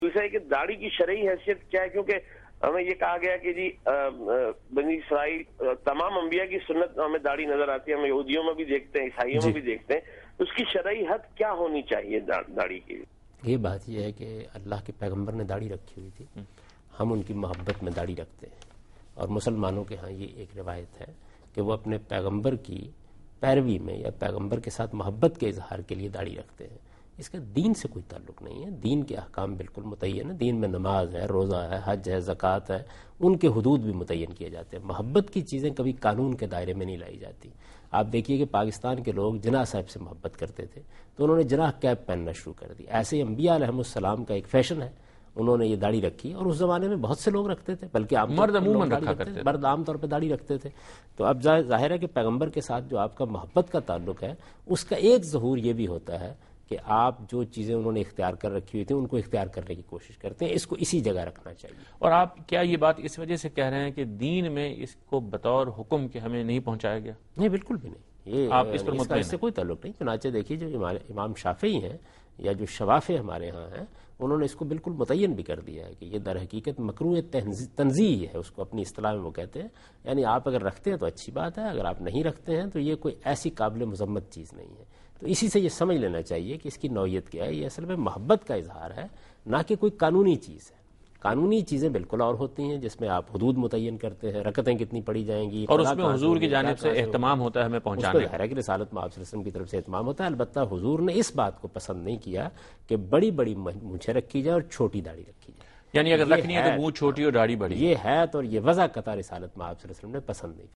Answer to a Question by Javed Ahmad Ghamidi during a talk show "Deen o Danish" on Duny News TV
دنیا نیوز کے پروگرام دین و دانش میں جاوید احمد غامدی ”ڈاڑھی کی شرعی حیثیت اور مقدار “ سے متعلق ایک سوال کا جواب دے رہے ہیں